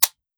fps_project_1/45 ACP 1911 Pistol - Dry Trigger 002.wav at 3121e9d4d222eacd86ec1b5a3408b2b51289efcf - fps_project_1 - Gitea: Git with a cup of tea